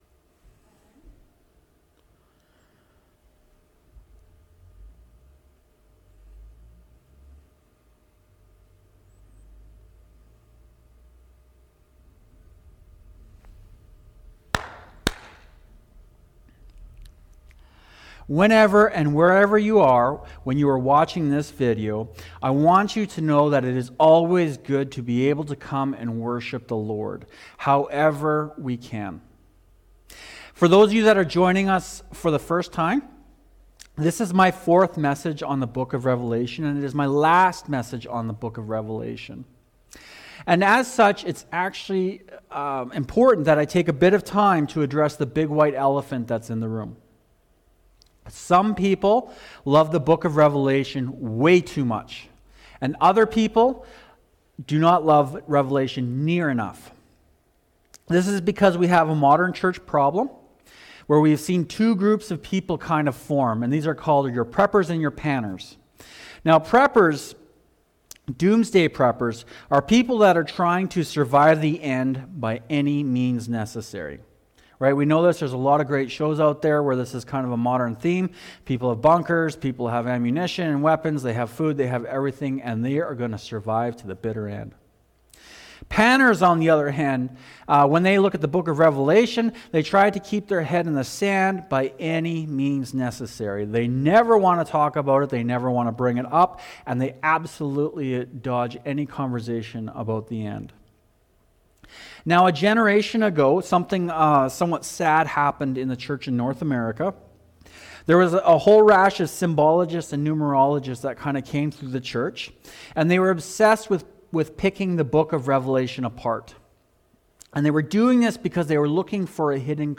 Heaven’s Right Around the Corner – Fairview Cornerstone Baptist Church